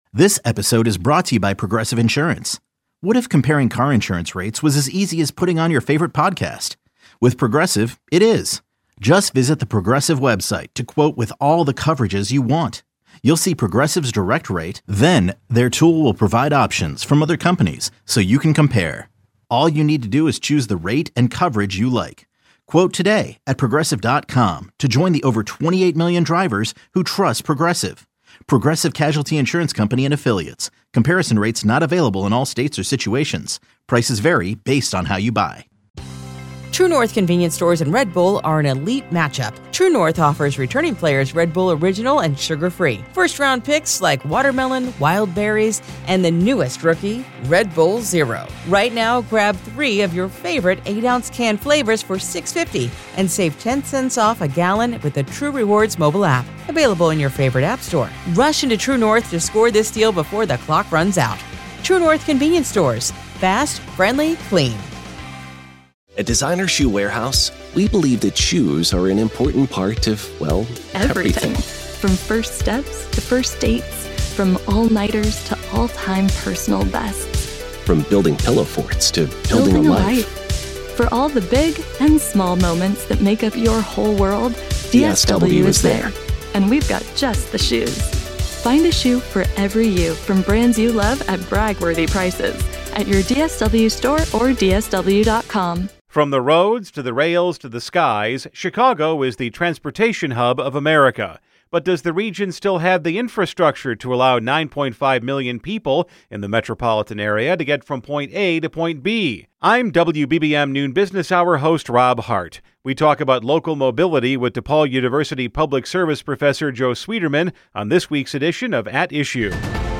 Public affairs interviews